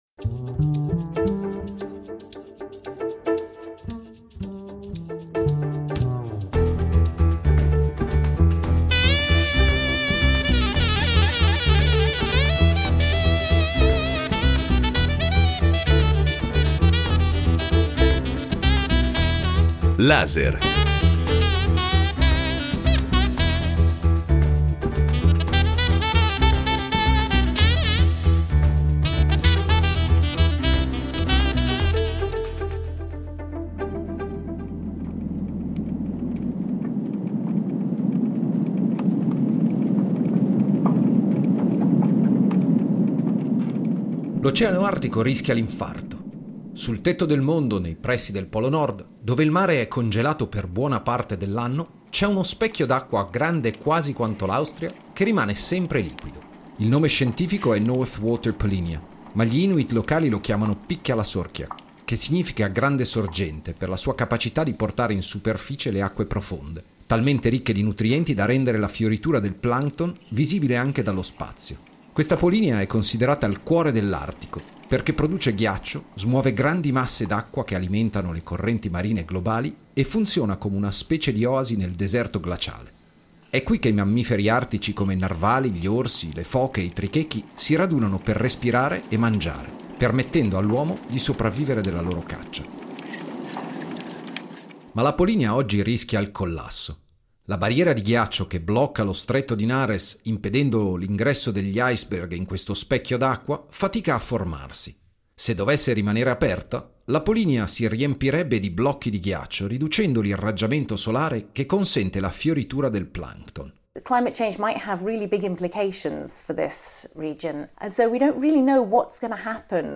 Reportage dal nord della Groenlandia
A queste latitudini, però, i cambiamenti climatici sono accelerati e stanno alterando le condizioni che hanno permesso alla polynya di fiorire per secoli, mettendo a rischio l’equilibrio del suo ecosistema e minacciando le comunità Inuit che vivono ai margini. Laser ha visitato Qaanaaq, in Groenlandia, uno degli insediamenti più a nord della terra affacciato sulla North Water Polynya, per scoprire gli effetti di questo collasso.